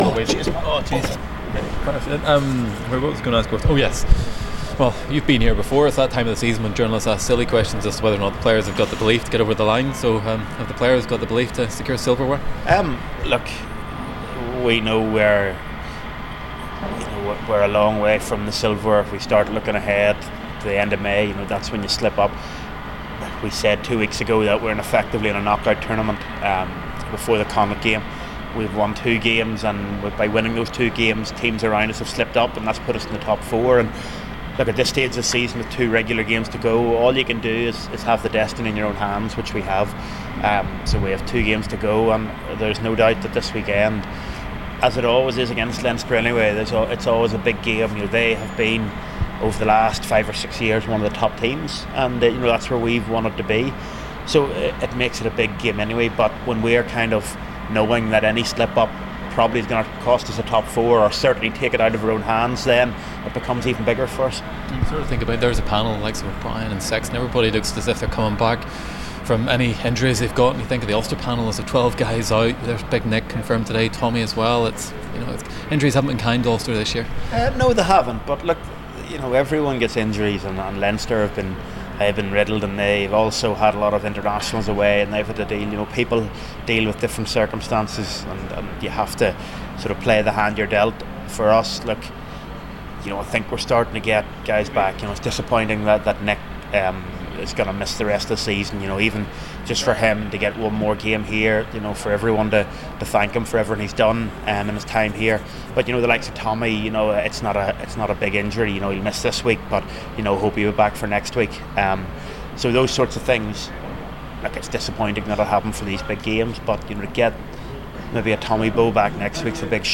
Rory Best and Les Kiss of Ulster speak to U105 Sport ahead of the weekend's big match